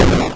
explode5.wav